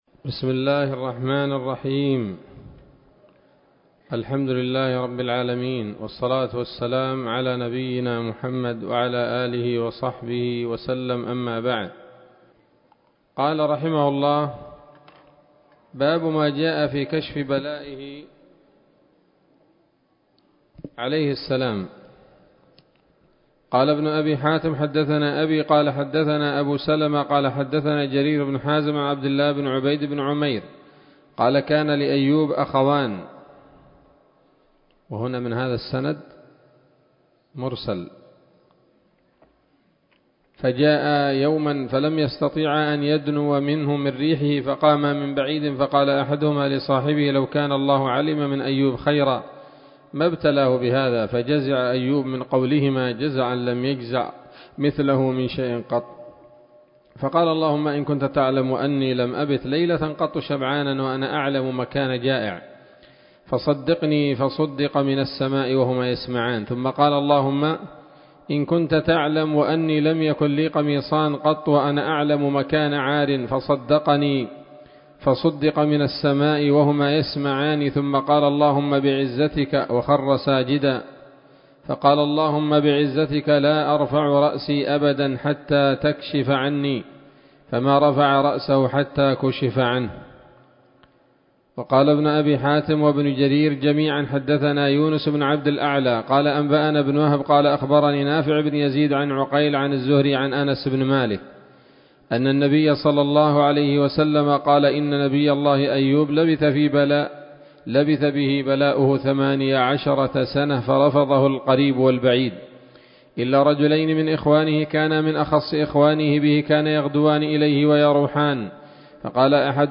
الدرس الخامس والسبعون من قصص الأنبياء لابن كثير رحمه الله تعالى